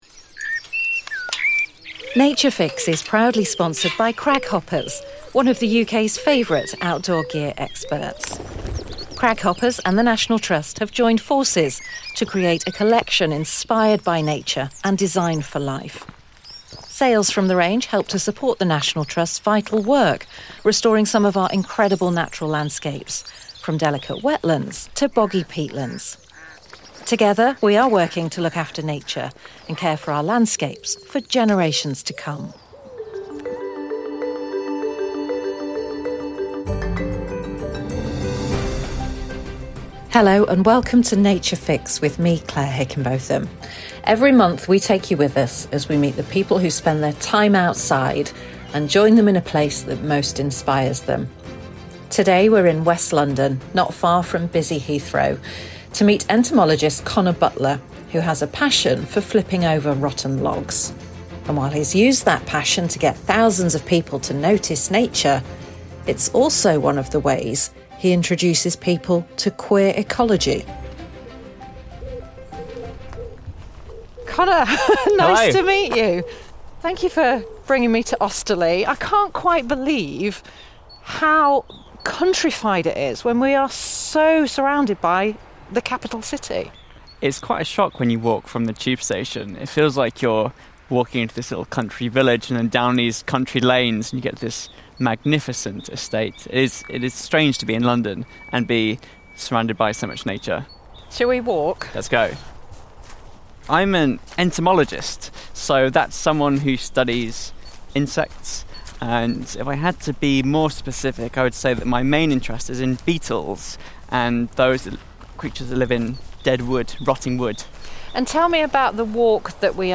Come on a fascinating queer ecology walk